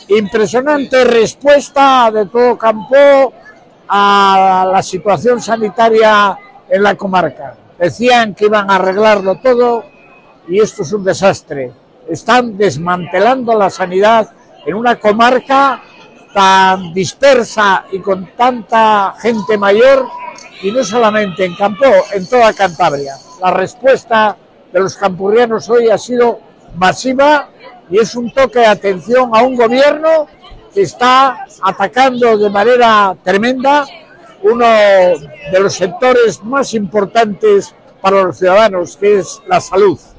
Finalmente, ha indicado que la de este sábado ha sido una de las mayores manifestaciones que él mismo ha vivido en Campoo y ha aplaudido el apoyo brindado por el comercio, con el cierre de sus establecimientos durante la protesta.
Ver declaraciones de Miguel Ángel Revilla, secretario general del Partido Regionalista de Cantabria.